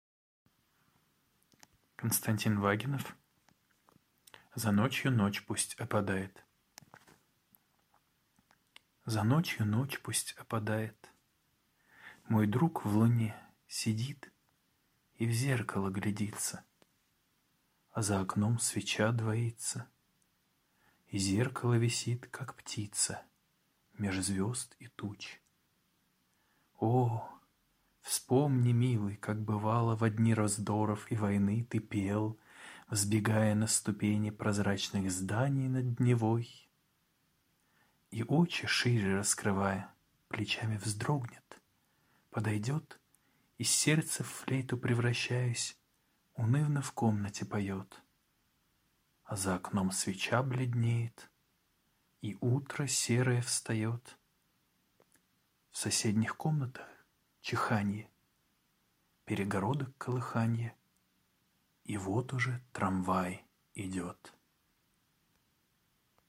1. «Читаю стихотворение К. Вагинова – За ночью ночь пусть опадает…» /